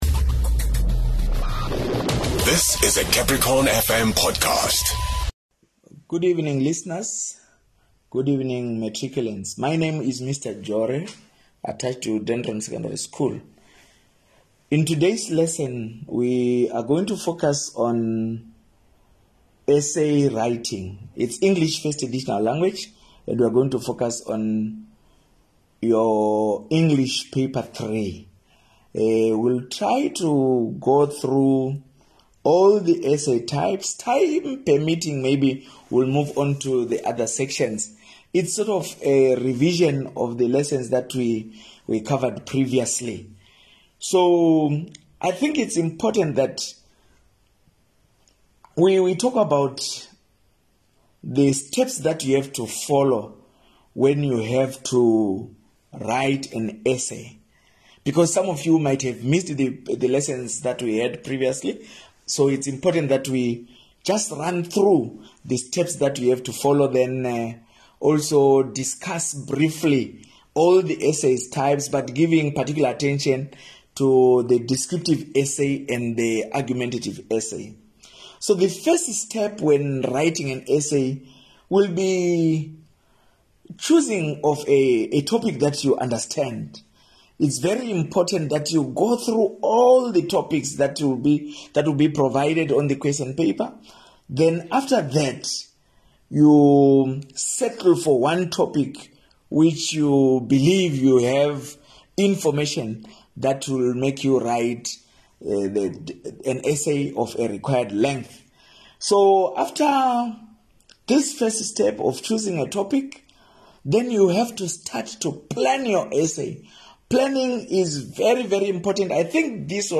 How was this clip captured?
As the year edges to an end, the Limpopo Department of Basic Education has dedicated time everyday on CapricornFM to helping Grade12 learners catch up on various lessons